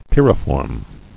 [pyr·i·form]